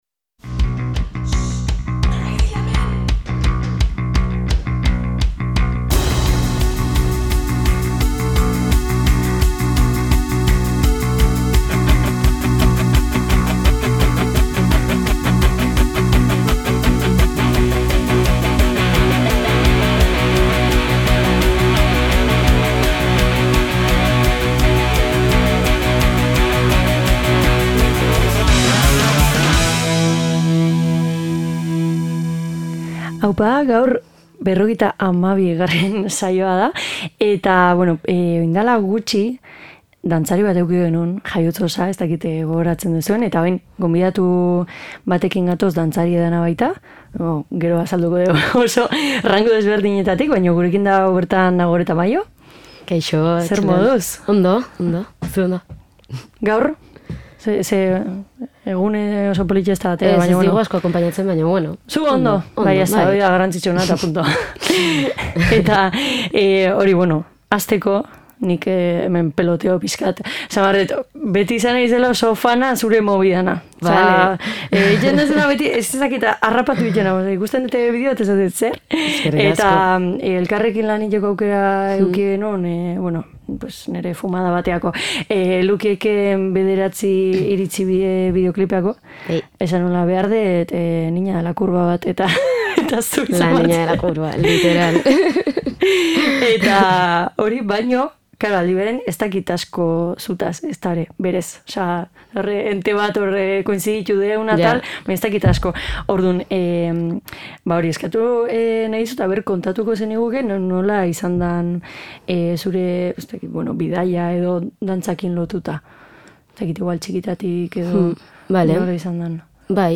Dantzarekin lotuta, horri eta musikari buruz hitz egiten egon gara (bizi-baldintzak, prozesuak, etab.). Zerrenda polita ekarri digu, gorputza lasaitzeko aproposa izan daitekeena.